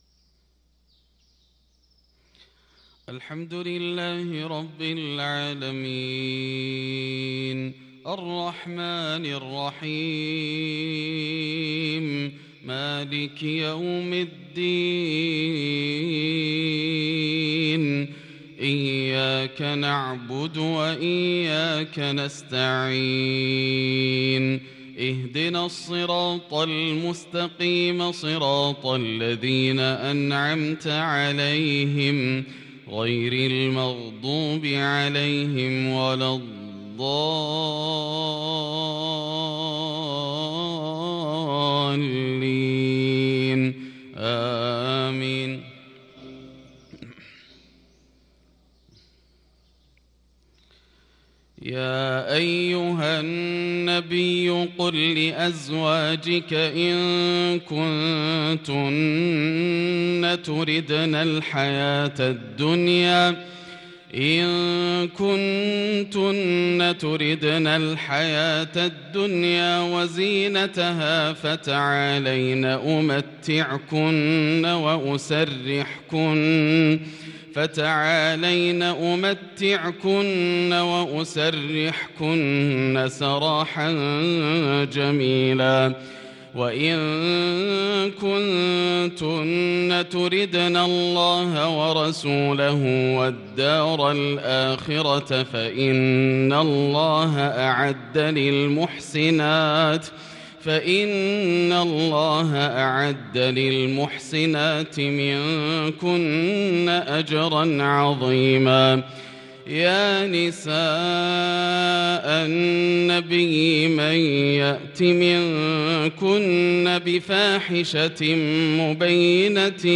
صلاة الفجر للقارئ ياسر الدوسري 24 جمادي الأول 1443 هـ
تِلَاوَات الْحَرَمَيْن .